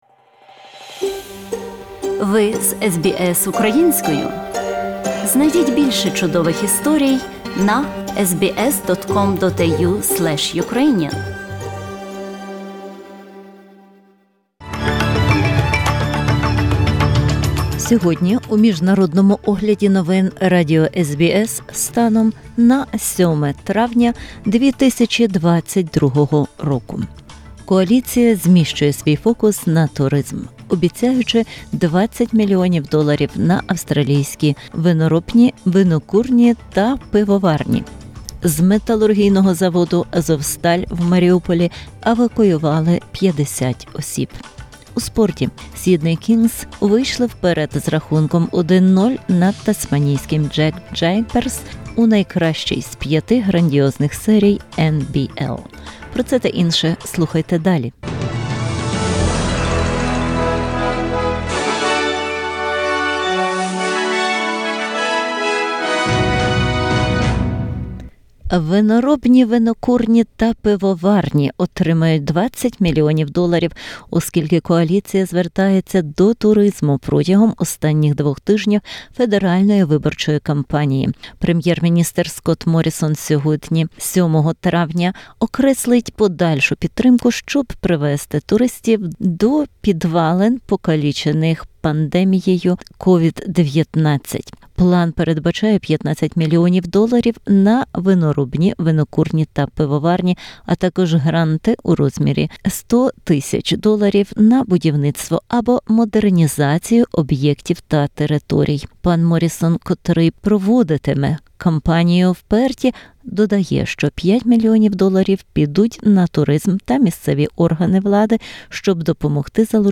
SBS news in Ukrainian - 7/05/2022